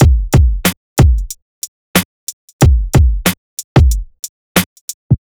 HP092BEAT1-L.wav